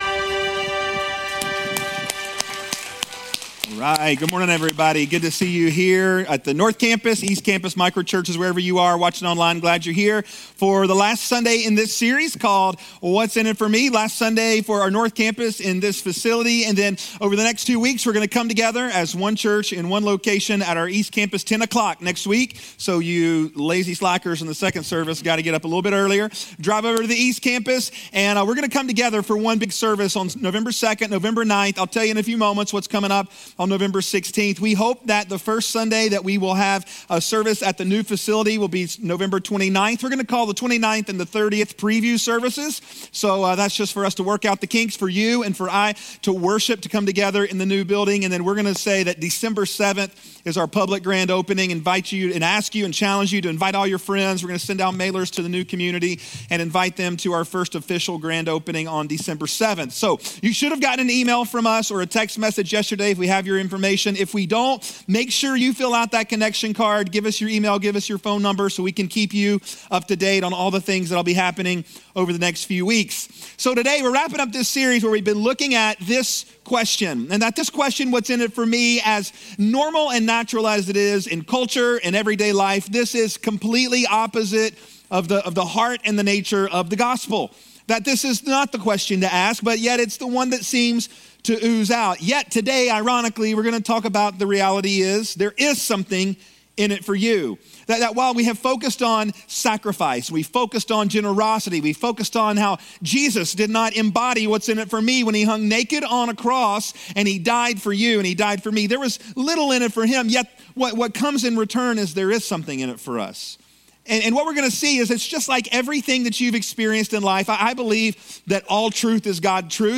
Most Recent Service